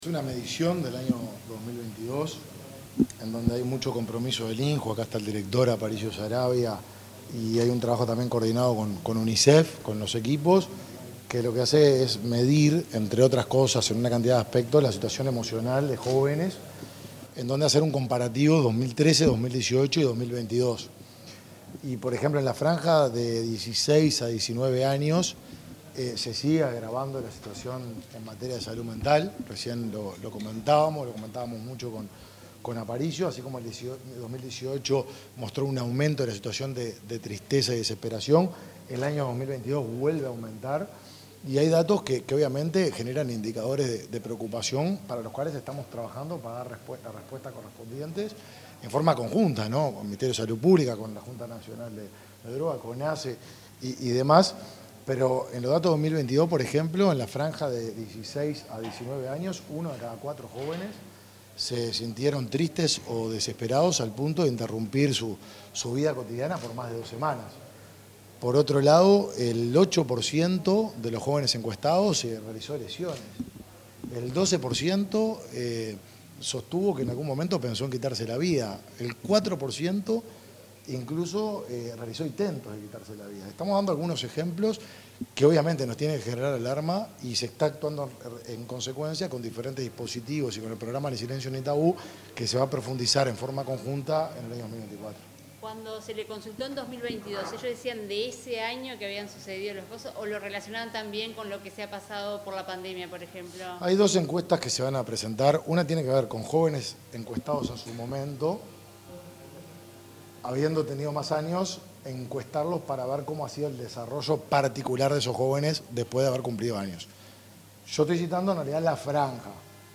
Declaraciones del ministro de Desarrollo Social, Martín Lema
Luego, el secretario de Estado dialogó con la prensa.